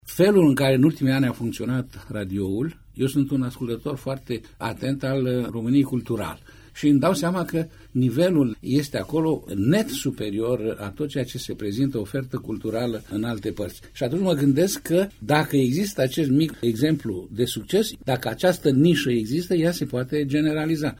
Propunerea de modificare a Legii 41, de organizare şi funcţionare a societăţilor publice de radio şi televiziune, a fost, astăzi, subiect de dezbatere la Radio Iaşi.